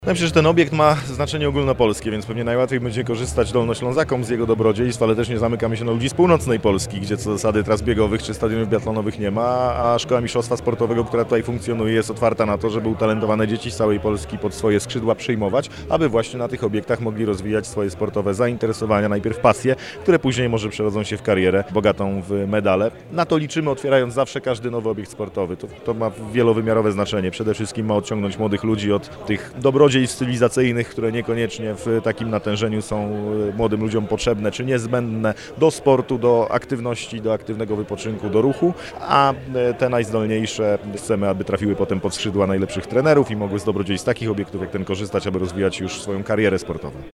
-Dolnośląskie Centrum Sportu ma znaczenie dla całej Polski, zaznacza minister sportu i turystyki.